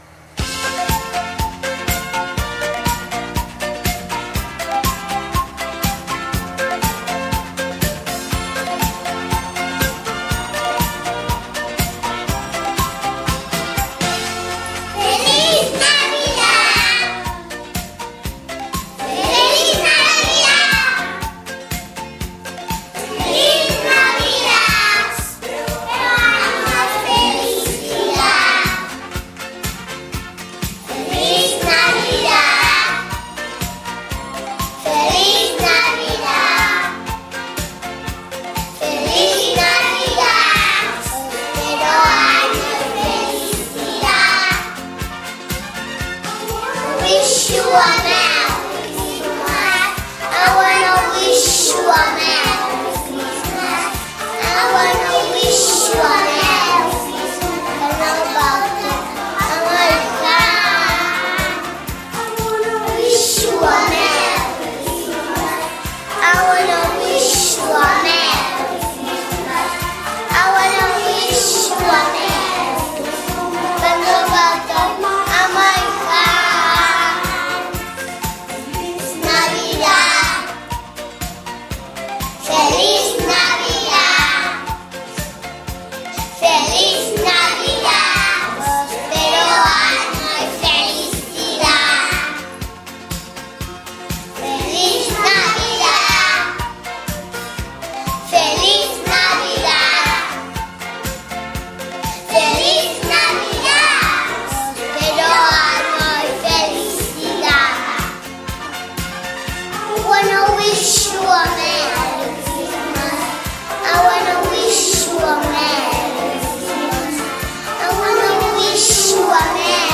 Els nens i nenes de P4 vam cantar una cançó de BONEY M molt animada que es diu